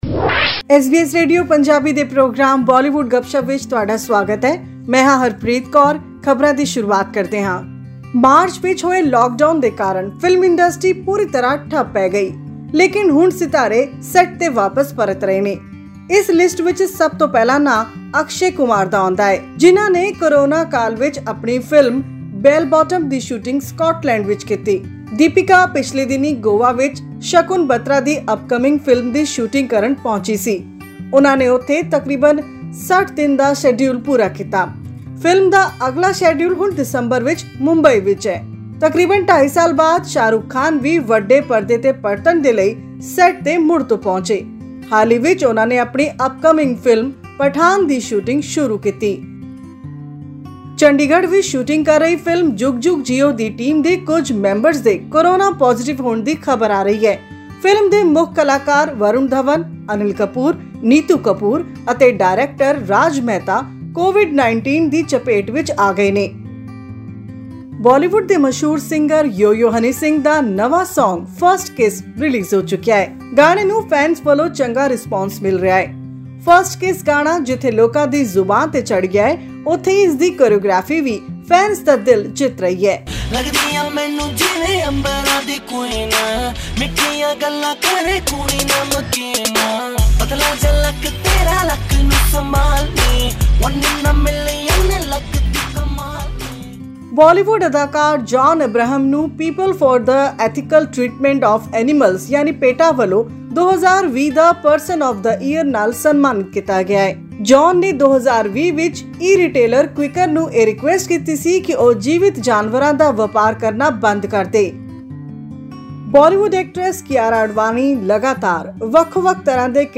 Akshay Kumar met the Chief Minister of Uttar Pradesh, Yogi Adityanath in Mumbai and discussed his upcoming movie Ram Setu's shooting locations in Ayodhya. This and much more in our weekly news bulletin from Bollywood.